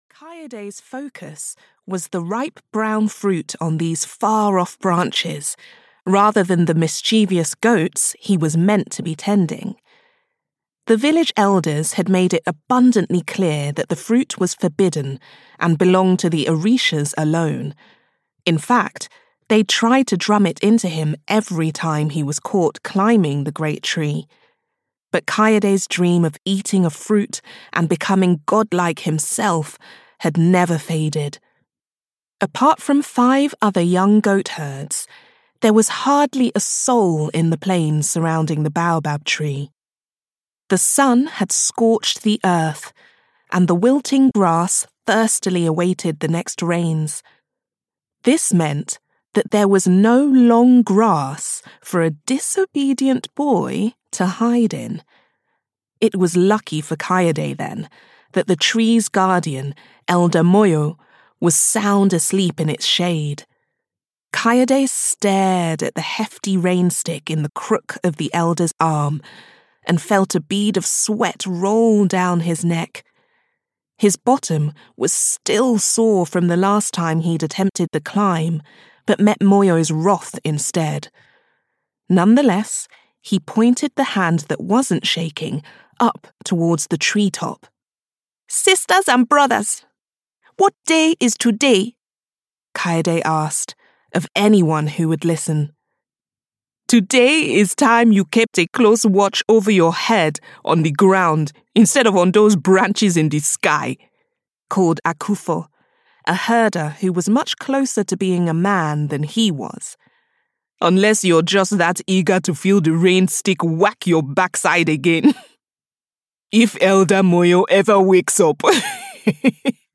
The Boy to Beat the Gods (EN) audiokniha
Ukázka z knihy